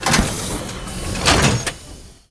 ElevatorClose1.ogg